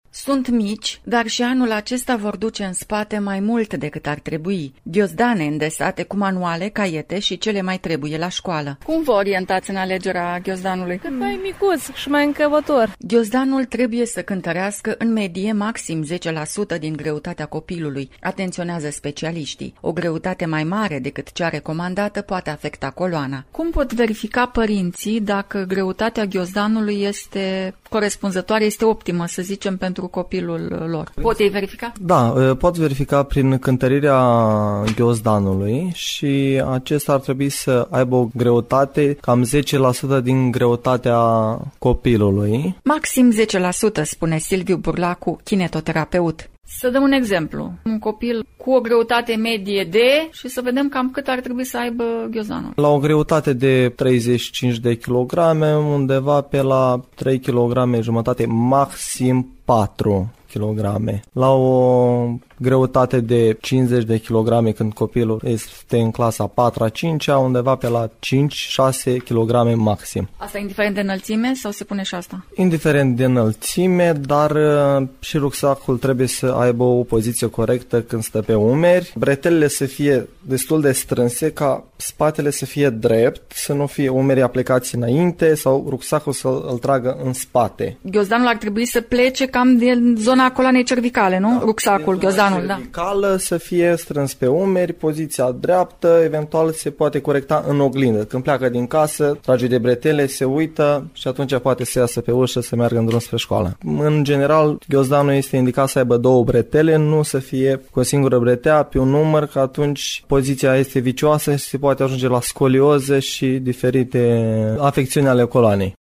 Amănunte, în reportajul următor: